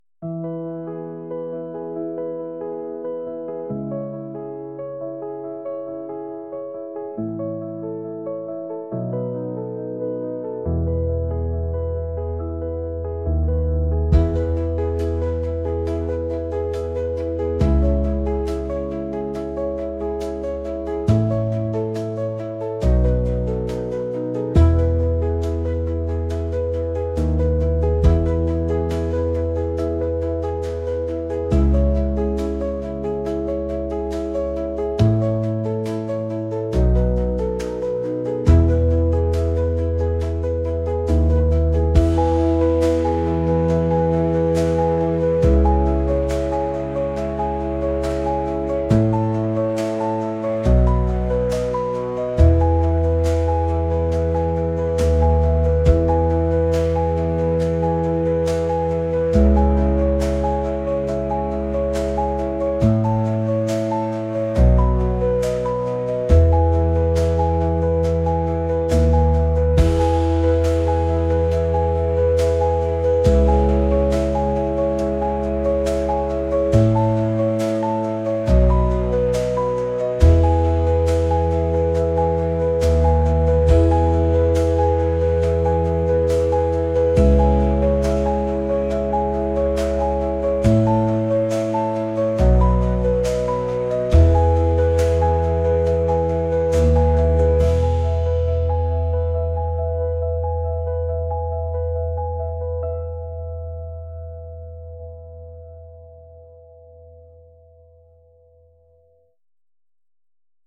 pop | indie | uplifting